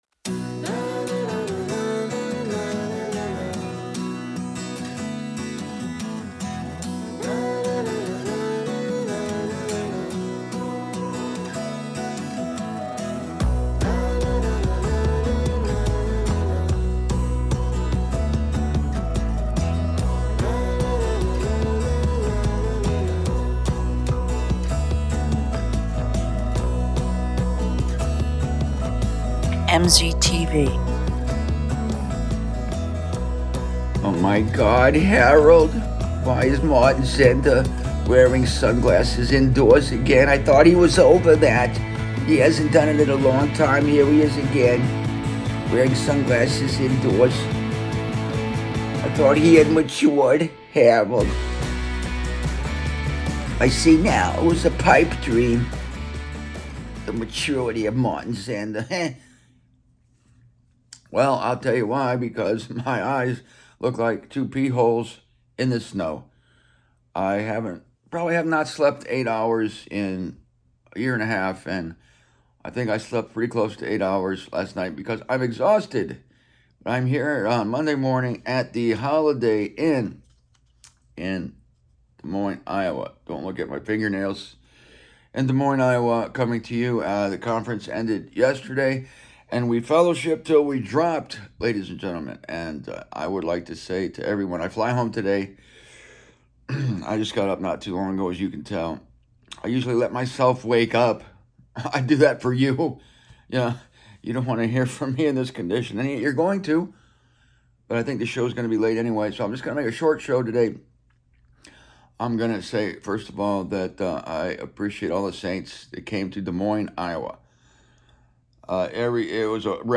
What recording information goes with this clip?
This was a point of discussion at the Des Moines conference, and I discuss it here, in a rather sleepy manner from my hotel room in Des Moines, as I am struggling to gain full consciousness.